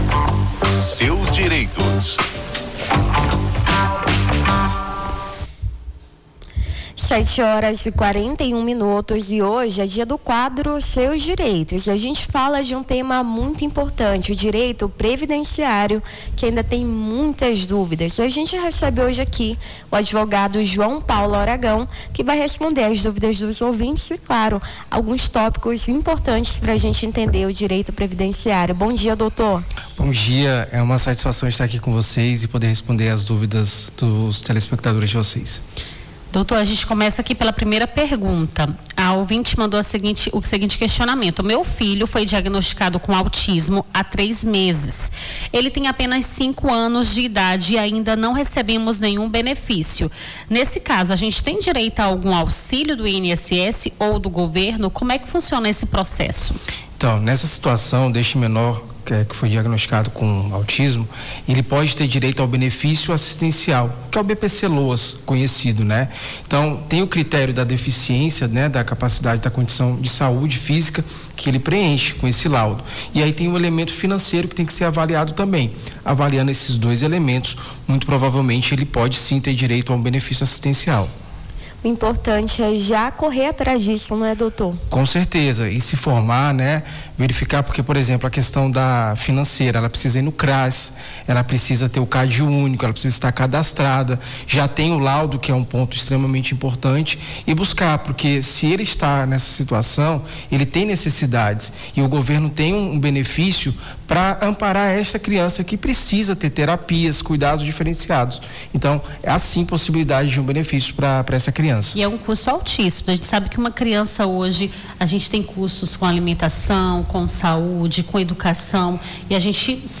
Seus Direitos: Advogado esclarece dúvidas sobre direito previdenciário